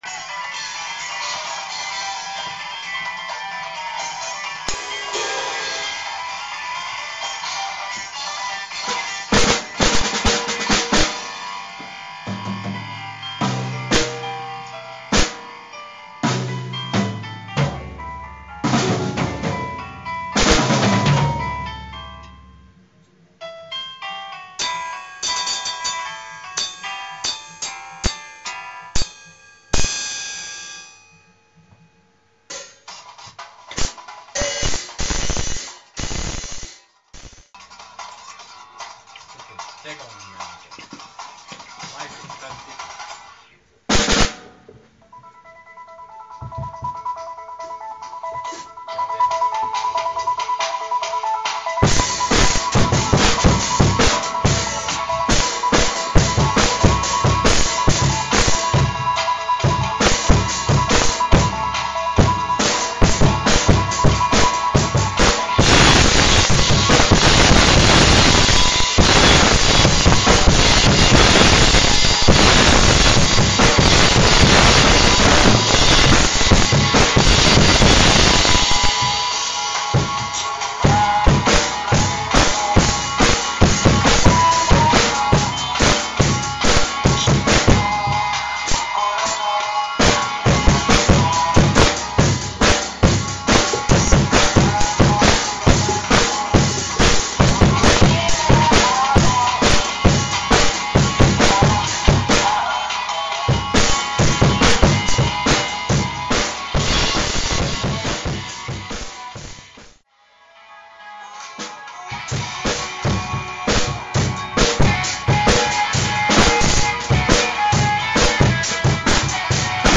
ペダルがアイアンコブラ！
ダイジェストでお送りしております
音割れは除去不可能です
ぐちゃぐちゃ！